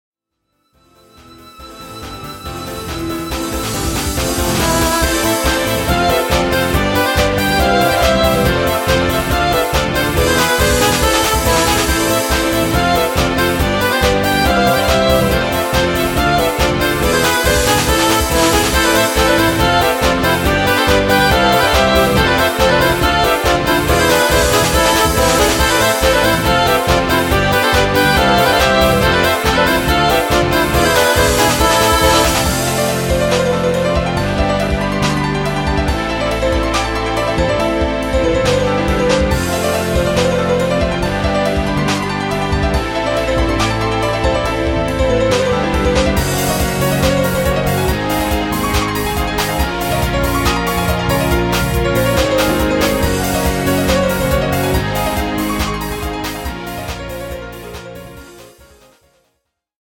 東方風自作曲